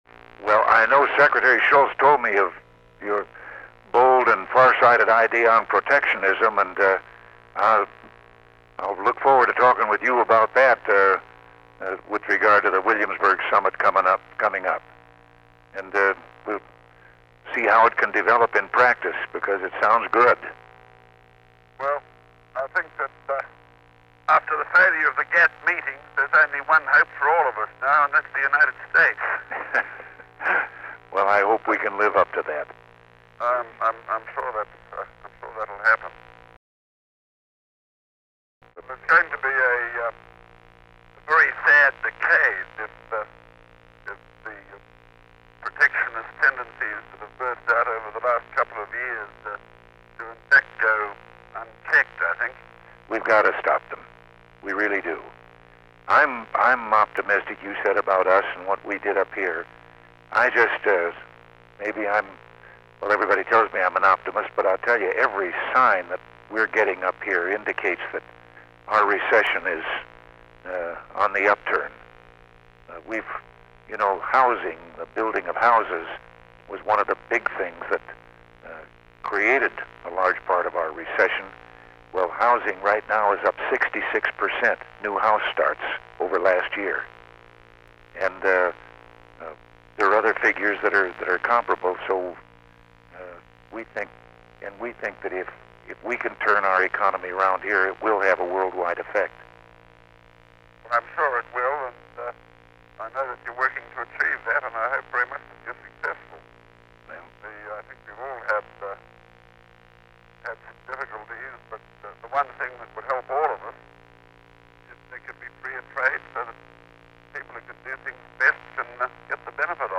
The Presidency / Featured Content 'Free Trade and Fair Trade' 'Free Trade and Fair Trade' Photo: Reagan White House Photographs In this exchange with Australian prime minister Malcolm Fraser, President Reagan expressed his goal for international trade and his optimism about the American economy. Both leaders wanted to combat the lurch toward protectionism, which Reagan sought to counter in May at the G-7 Summit in Williamsburg, Virginia. Date: January 13, 1983 Location: Situation Room Tape Number: Cassette 12A Participants Ronald W. Reagan Malcolm Fraser Associated Resources Annotated Transcript Audio File Transcript